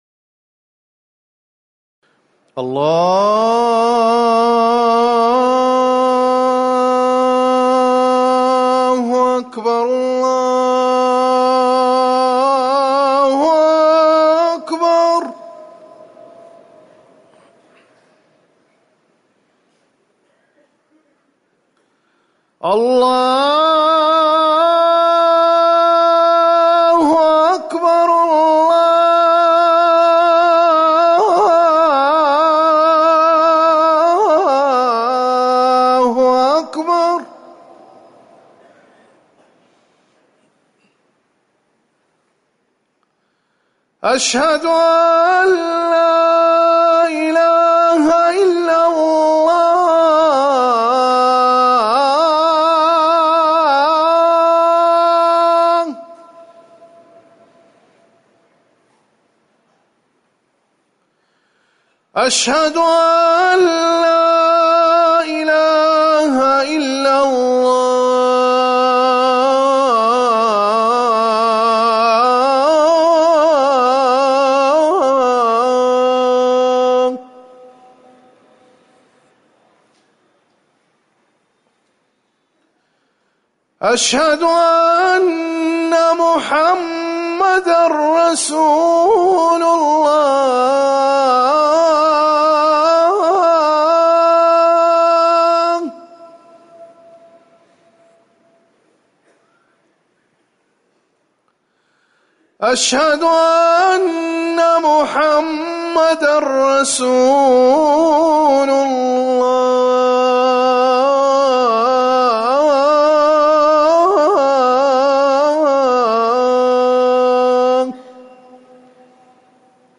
أذان العصر
تاريخ النشر ٢٣ صفر ١٤٤١ هـ المكان: المسجد النبوي الشيخ